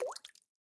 drip_water_cauldron6.ogg